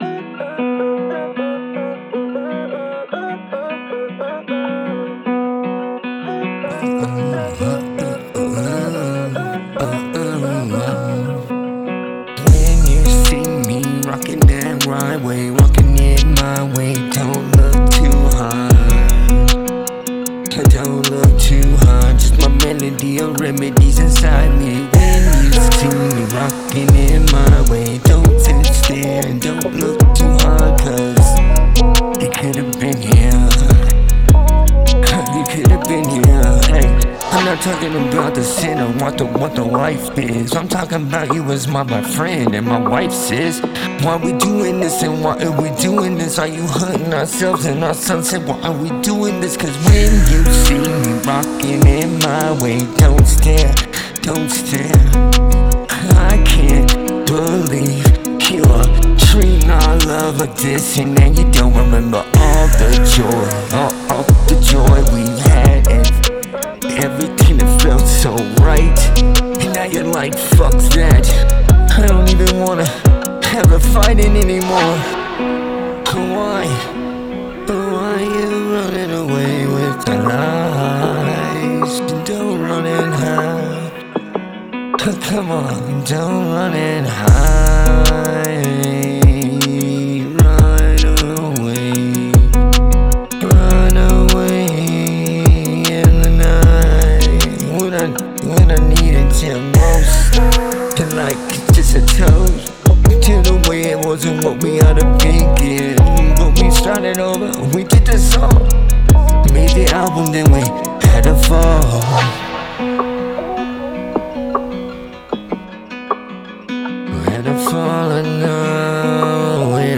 Date: 2026-04-13 · Mood: dark · Tempo: 77 BPM · Key: C major